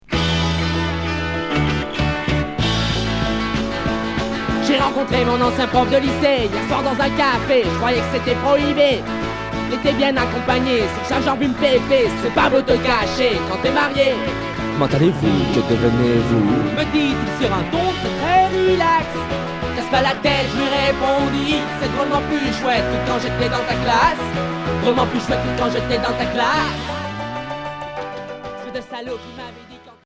Punk belge Unique 45t retour à l'accueil